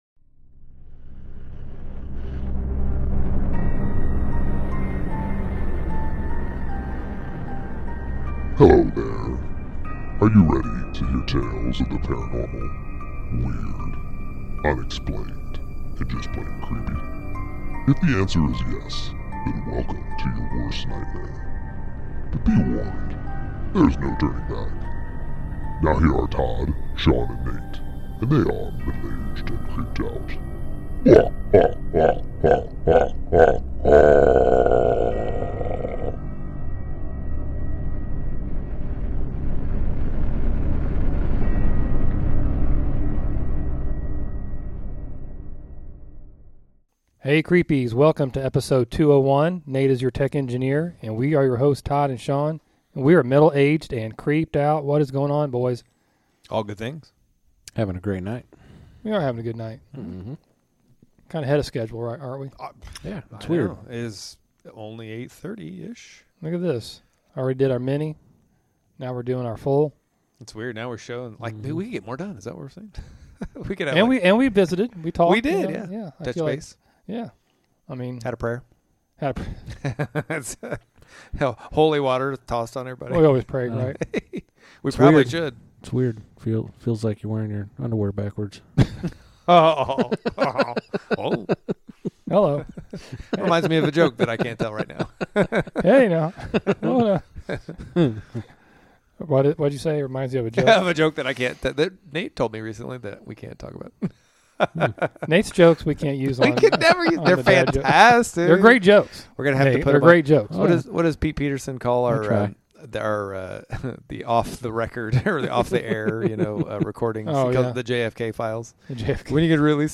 Guest Chat
The guys have an outstanding, deep and inspiring guest chat with our new friend and fellow podcaster